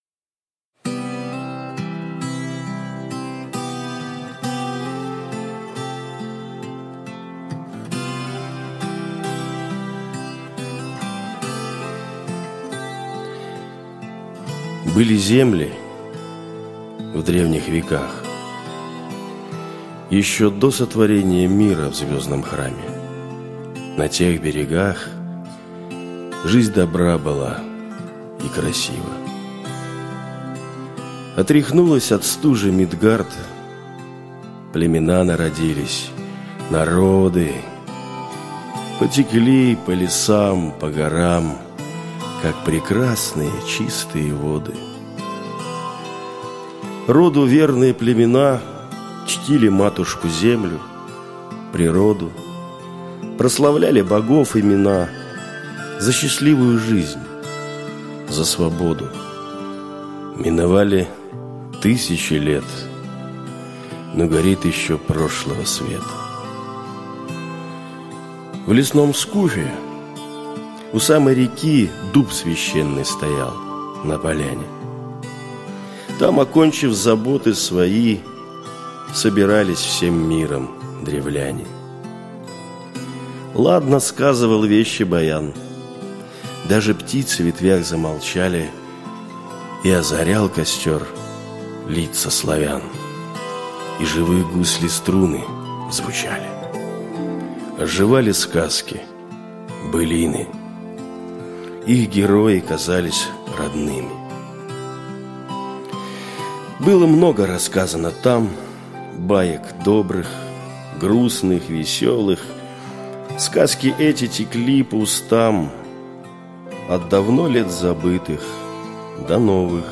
Aудиокнига Тень Волота.